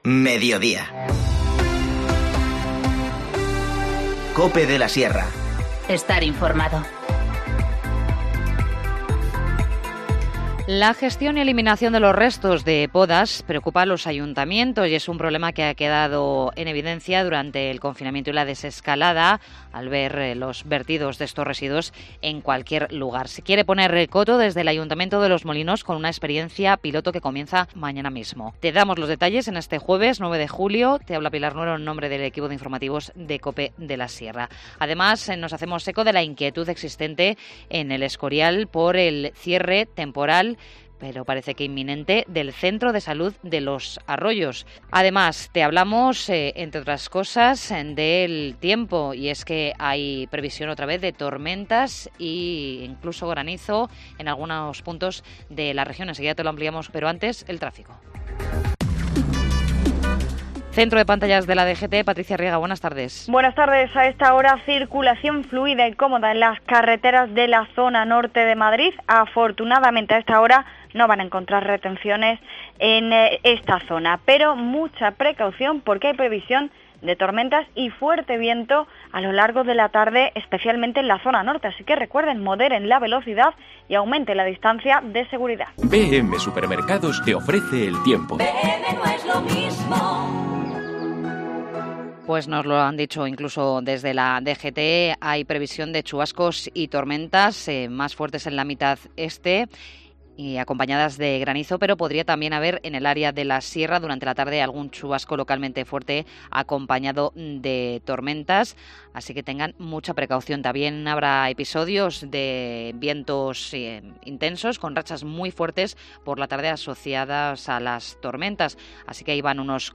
Informativo Mediodía 9 julio 14:20h